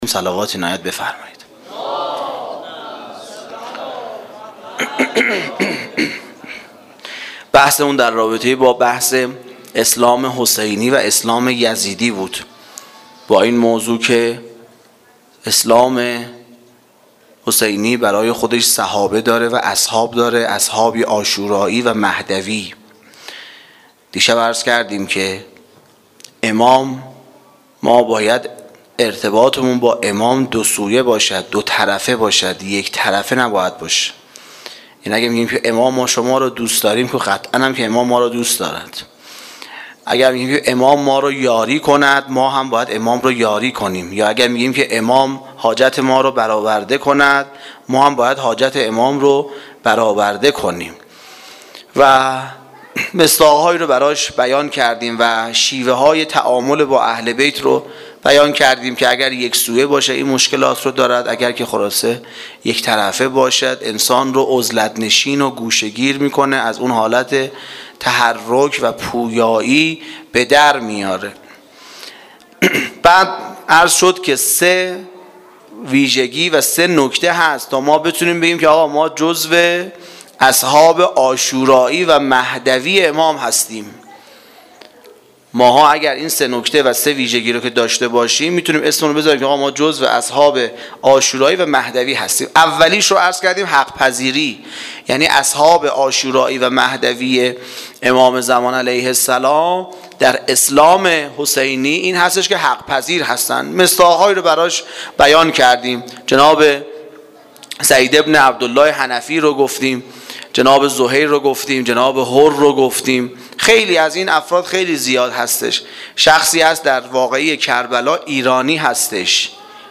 سخنرانی شب نهم محرم
Sokhanrani-Shabe-09-moharram94.mp3